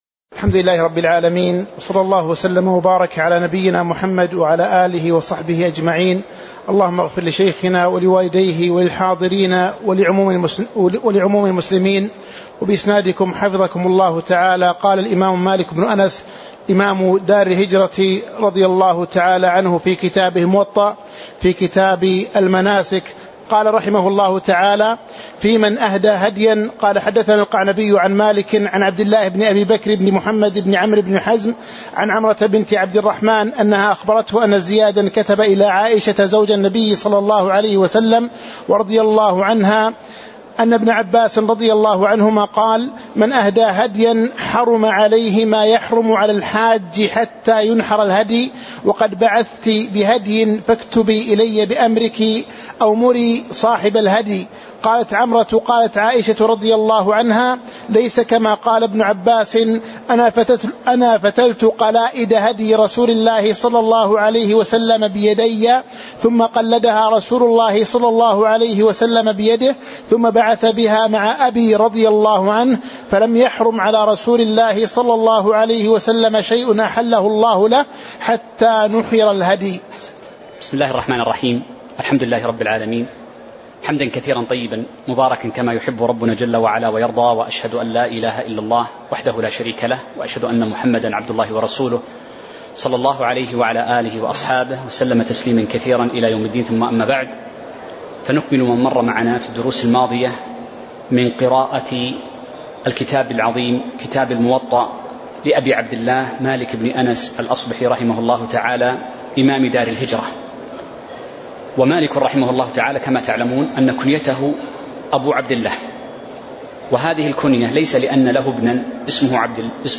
تاريخ النشر ٢٥ ذو الحجة ١٤٤٦ هـ المكان: المسجد النبوي الشيخ